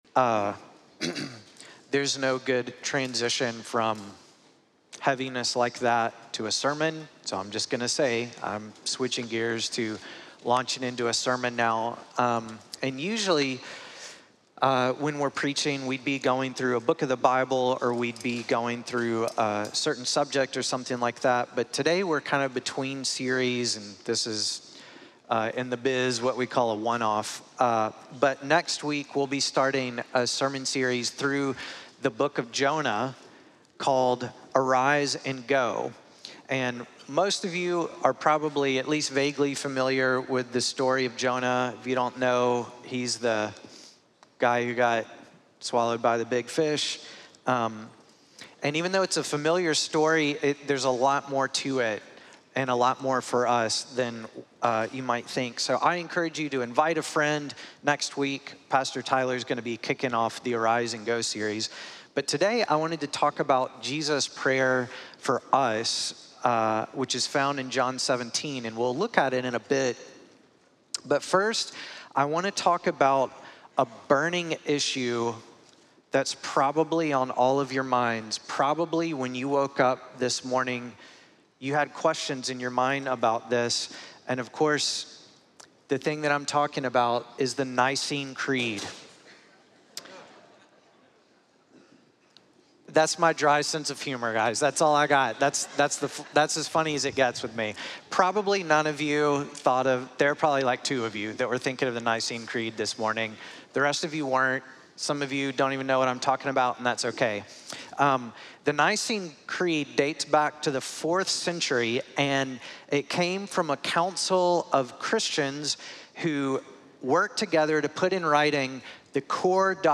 Orangewood Church Maitland Orlando Florida